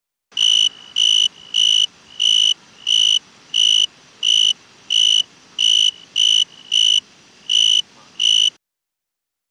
树蟋